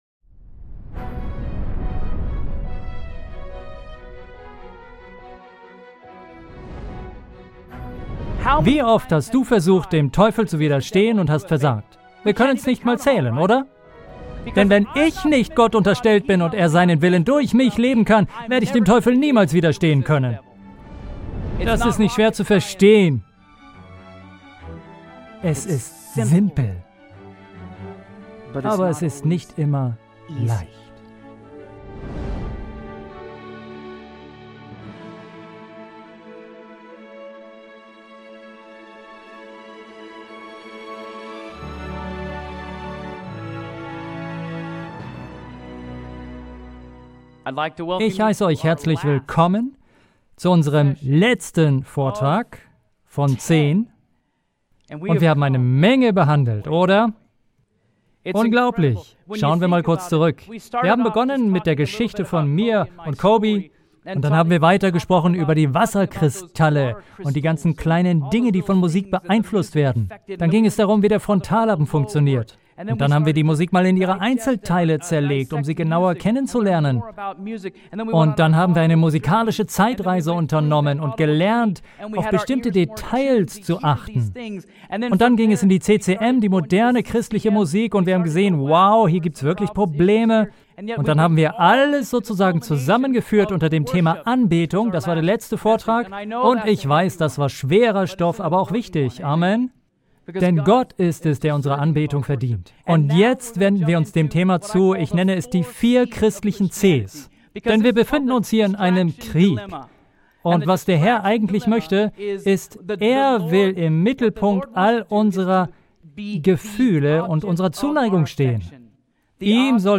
Dann bist du bei diesem Seminar genau richtig.